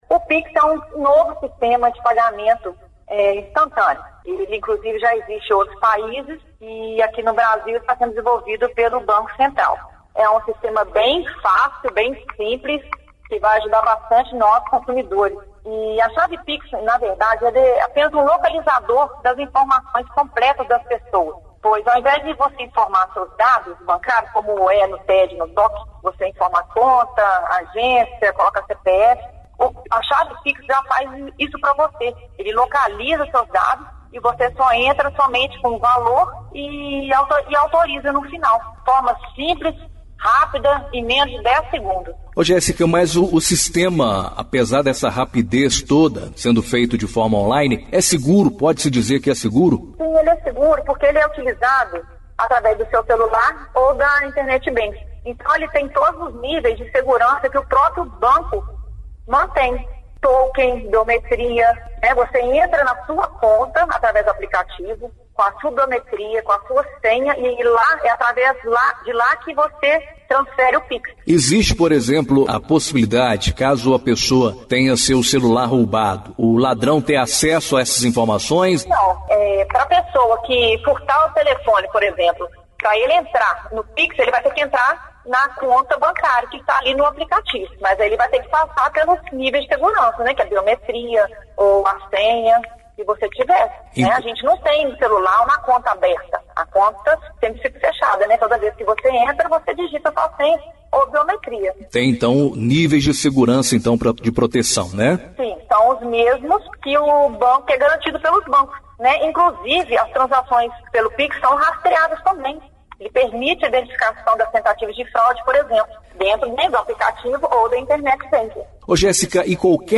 Entrevista exibida na Rádio Educadora AM/FM